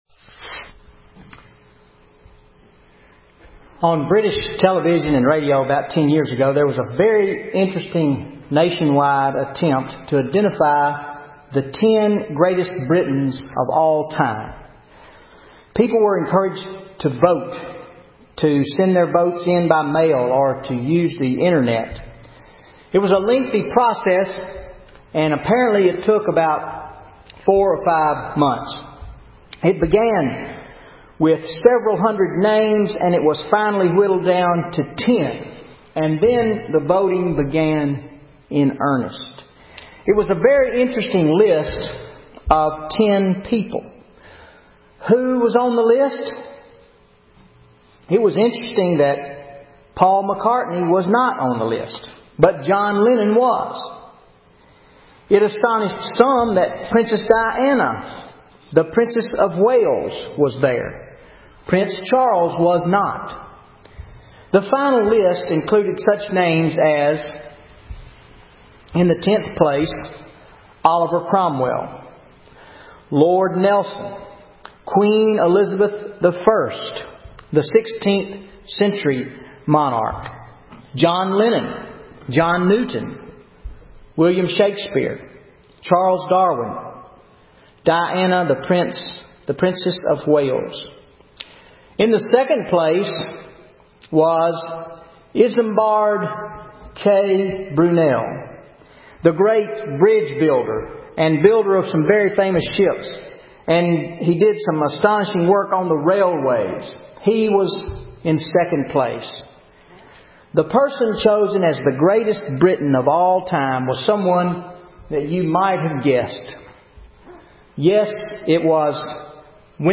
0 Comments VN810135_converted Sermon Audio Previous post Sermon March 17